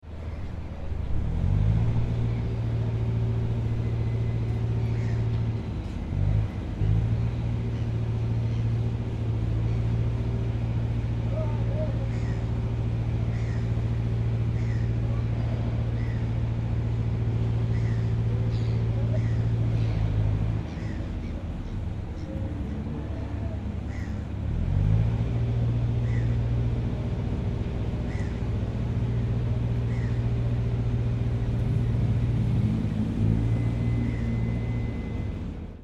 Звук Автокран работает на дистанции (записано в городе) (00:36)